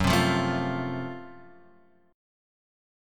F#mM7b5 chord